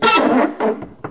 creak3a.mp3